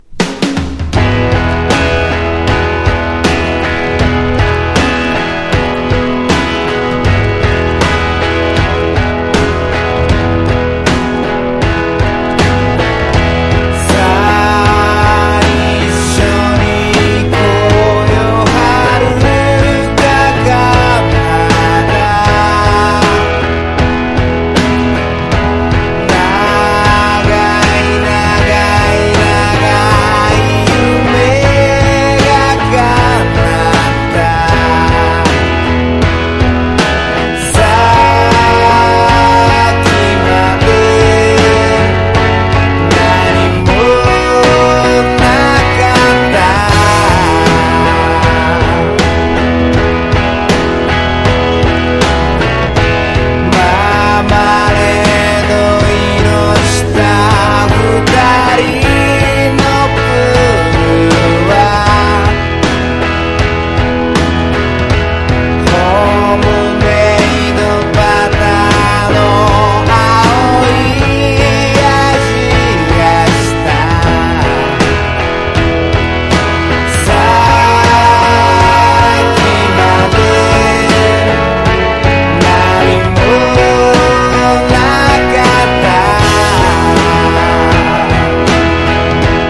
POP
ポピュラー# 90-20’S ROCK